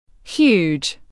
Huge /hjuːdʒ/